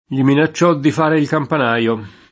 campanaro [kampan#ro] s. m. — meno com. campanaio [kampan#Lo]; pl. -nai — es.: Gli minacciò di fare il campanajo [